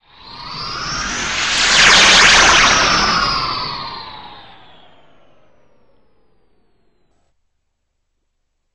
teleport2.ogg